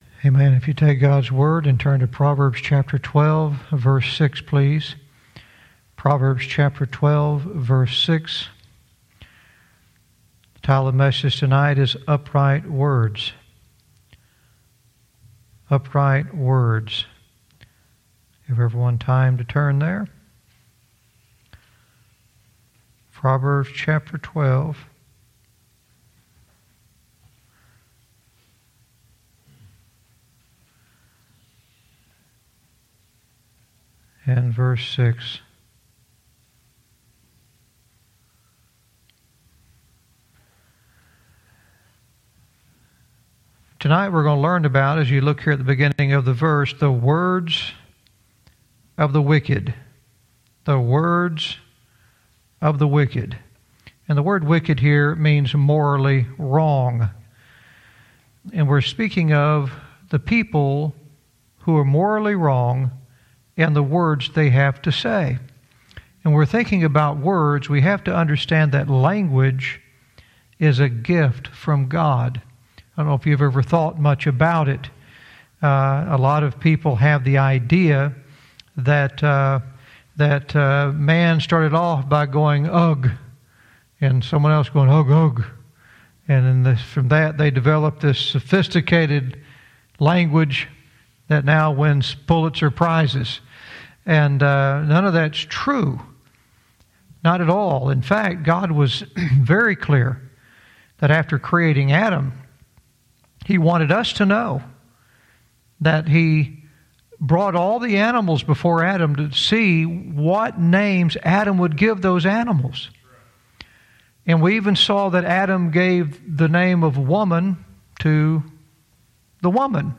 Verse by verse teaching - Proverbs 12:6 "Upright Words"